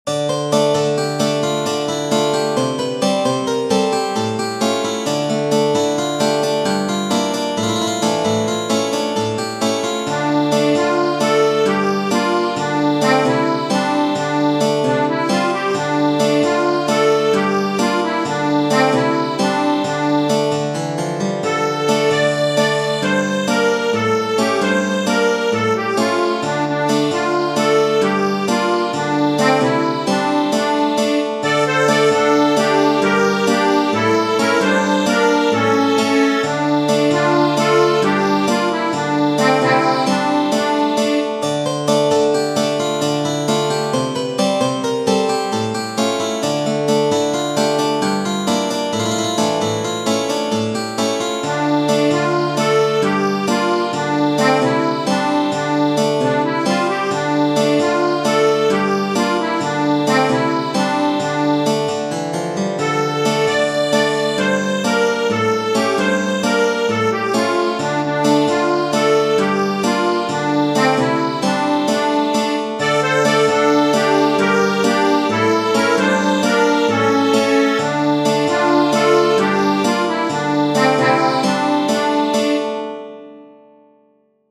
Tradizionale Genere: Folk Ej more Dončo meraklija 1.